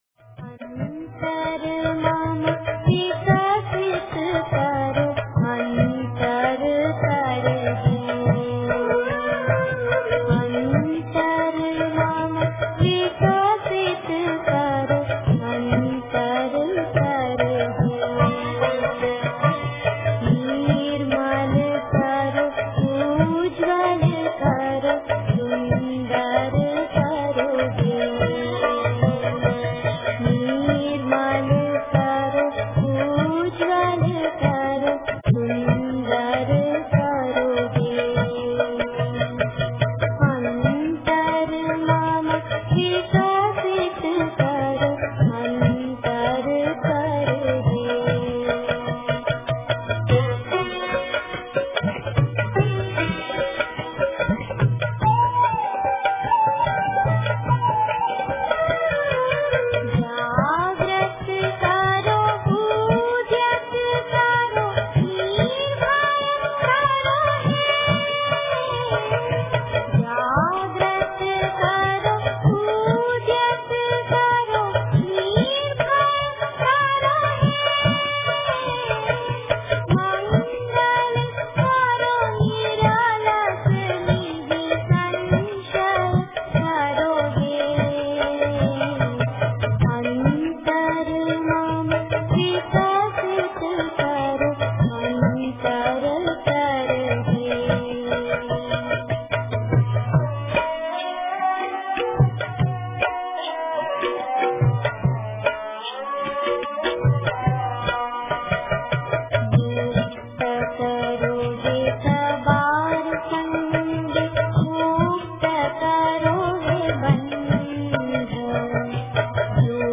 અંતર મમ વિકસિત કરો - Antar Mam Vikasit Karo - Gujarati Kavita - લોક ગીત (Lok-Geet) - Gujarati World